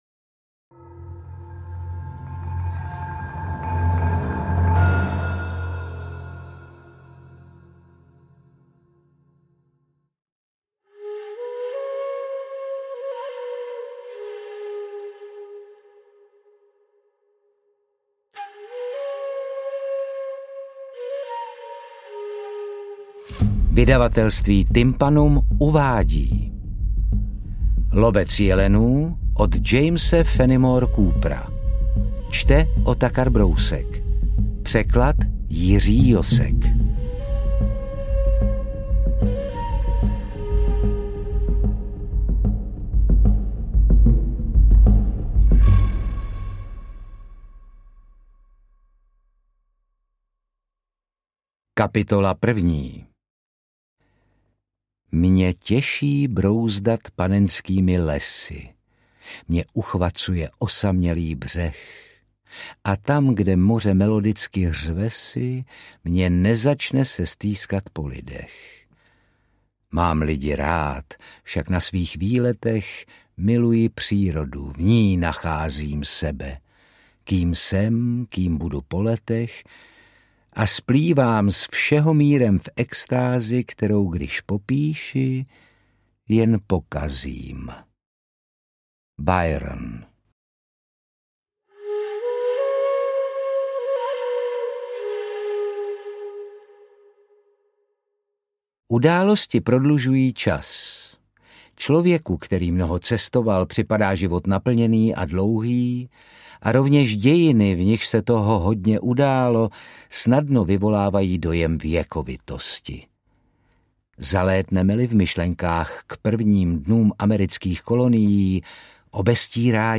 Interpret:  Otakar Brousek
AudioKniha ke stažení, 35 x mp3, délka 14 hod. 41 min., velikost 806,1 MB, česky